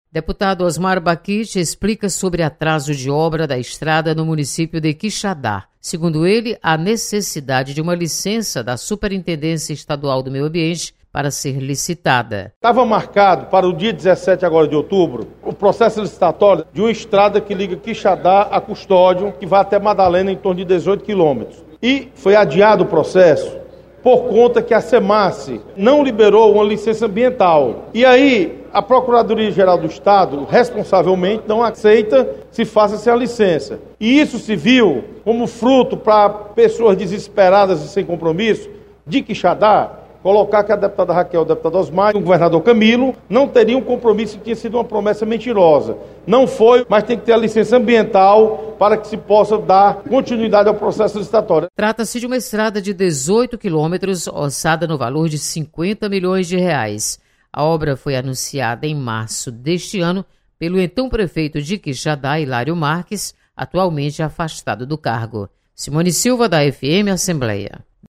• Fonte: Agência de Notícias da Assembleia Legislativa